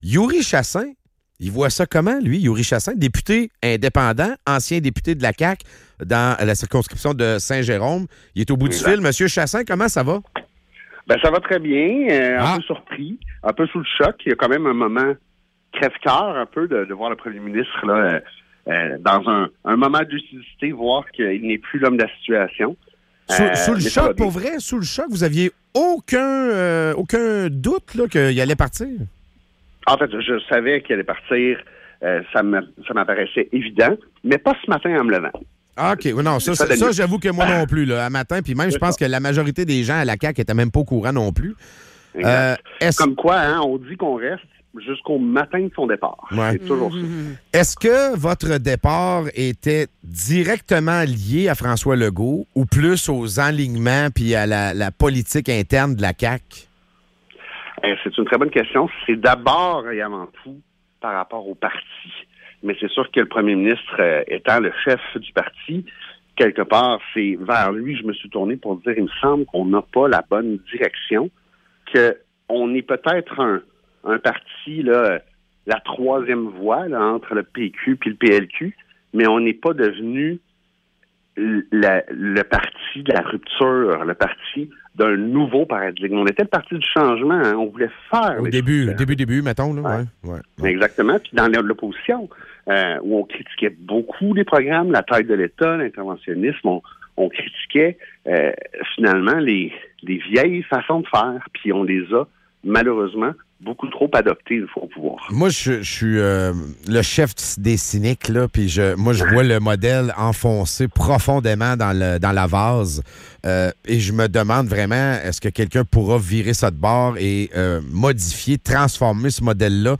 En entrevue, Youri Chassin.